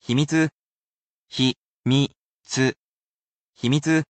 himitsu